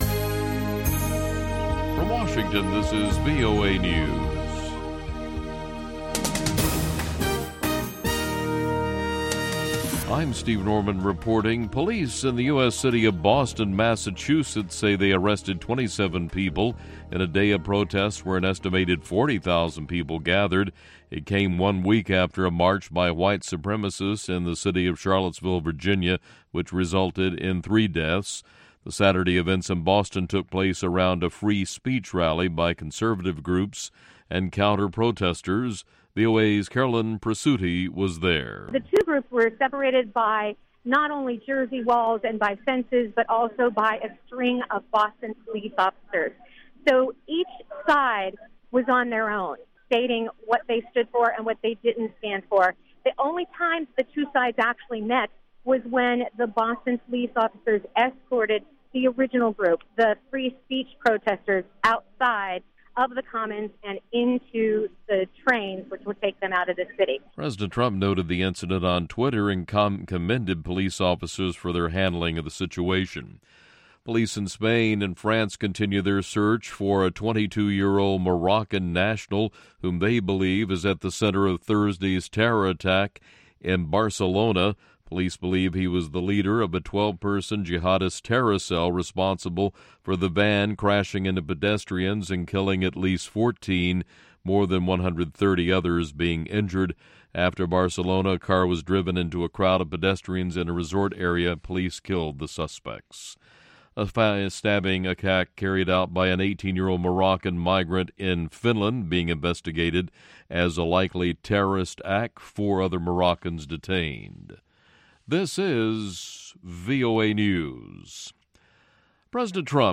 Soak in the sounds of gorgeous tunes from all over Africa.